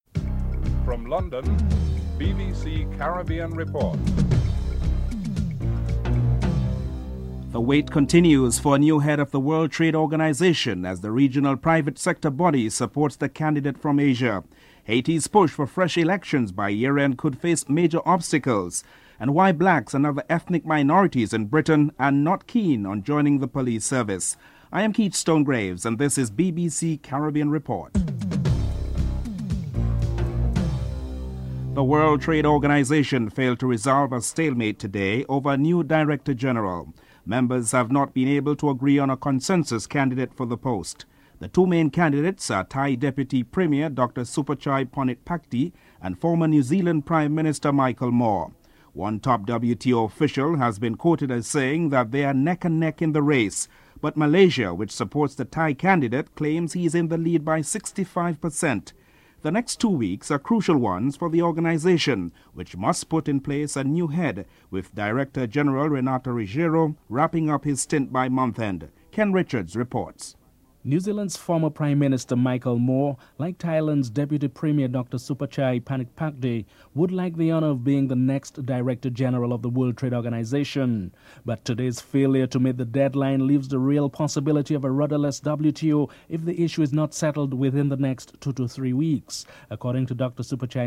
6. Venezuelan President Hugo Chavez addresses the nation in the midst of a political crisis in which political opponents view his leadership as changing into a dictatorship (14: 25 - 15: 29)